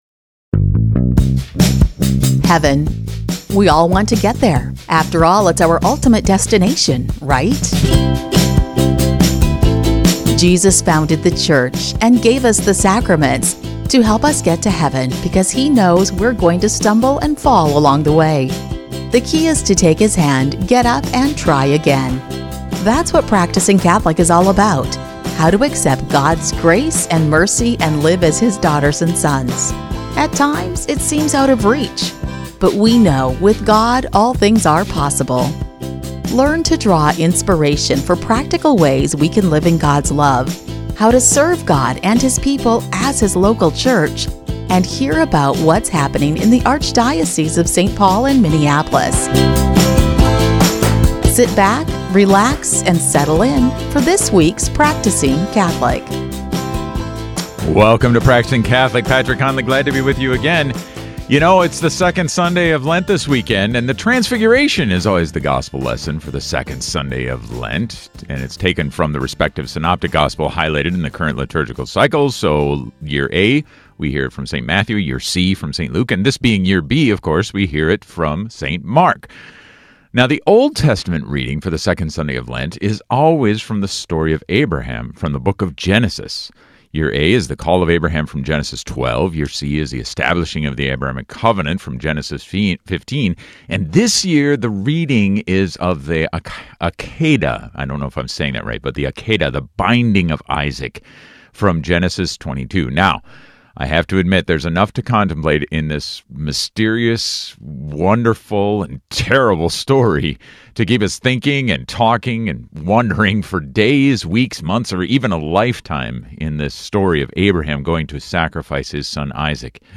On Practicing Catholic, join us for a conversation with not one, but two bishops!